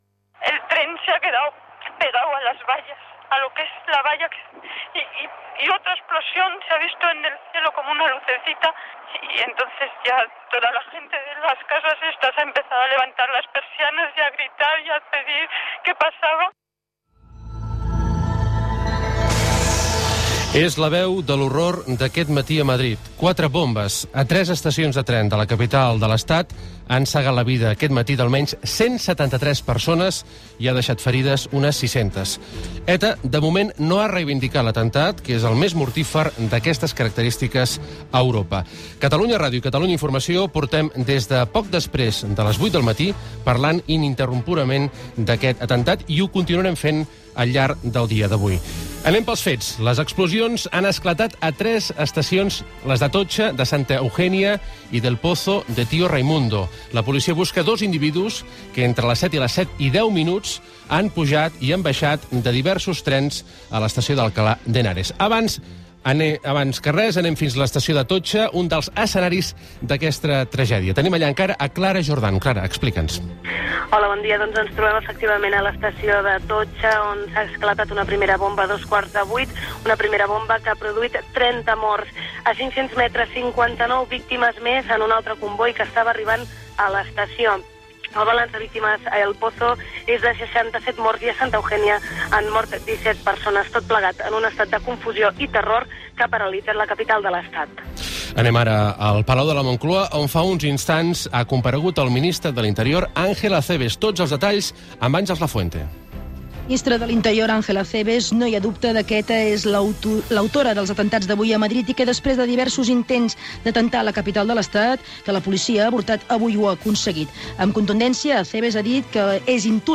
008b806b88c2baac5ef3c70c142262c6e62bd478.mp3 Títol Catalunya Ràdio Emissora Catalunya Ràdio Cadena Catalunya Ràdio Titularitat Pública nacional Nom programa L'informatiu del migdia (Catalunya Ràdio) Descripció Declaració d'una testimoni. Resum informatiu i declaracions sobre de l'atemptat fet a trens de rodalies en tres estacions de Madrid. El ministre d'interior Acebes declara que l'autora és ETA. Arnaldo Otegui afirma que ETA no ha fet aquests atemptats.
Gènere radiofònic Informatiu